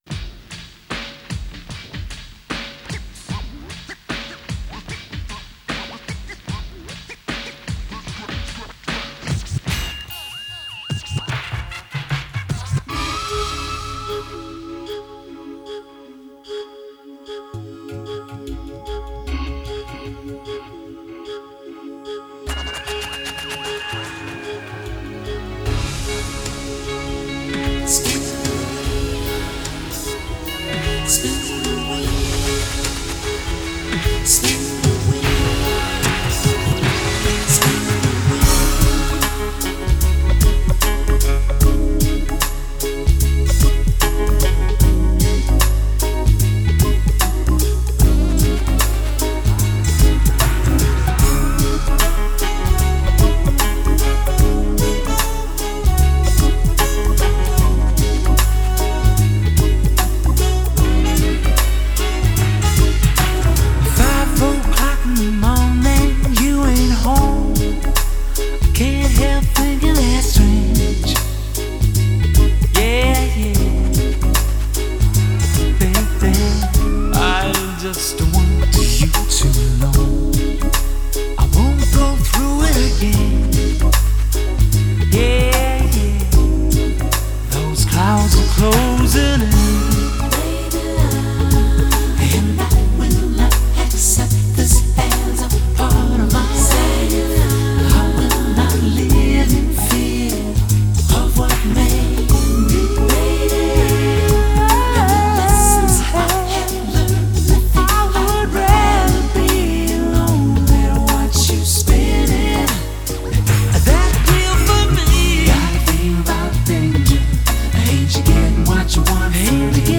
brooding darkness of trip-hop